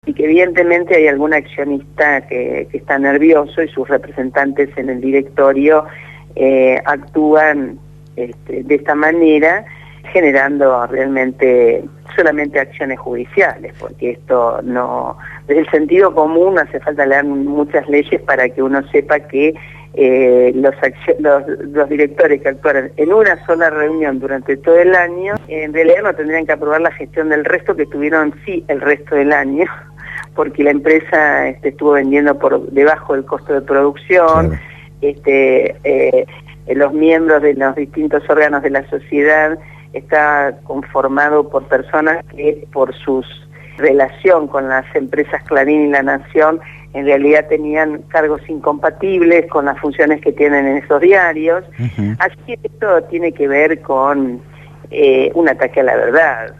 Beatriz Paglieri, Directora de Papel Prensa por el Estado Nacional, fue entrevistada en «Patas Cortas» (Sábado, de 08:00 a 10:00 hs).
En una jugosa entrevista, a horas de presentarse el informe «La Verdad sobre Papel Prensa».